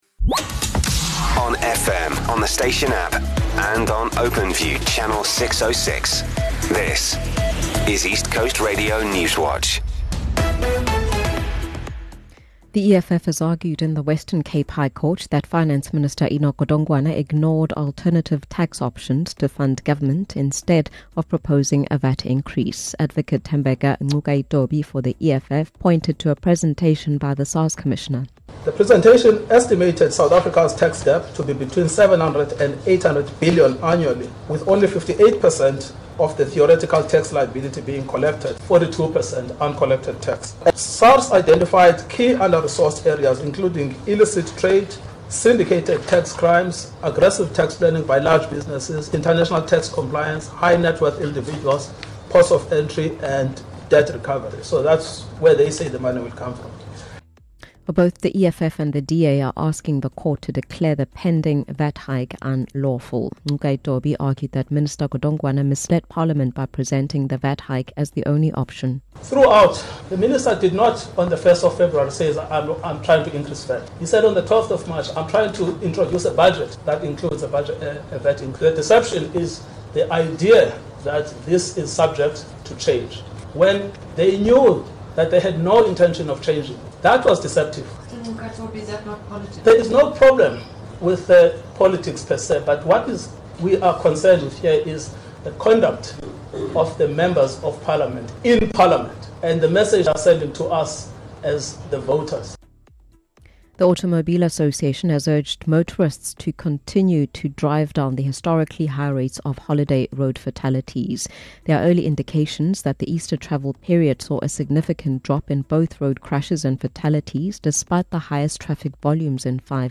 East Coast Radio Newswatch is the independent Durban-based radio station's news team. We are KwaZulu-Natal’s trusted news source with a focus on local, breaking news. Our bulletins run from 6am until 6pm, Monday to Friday.